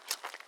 Water Walking 1_02.wav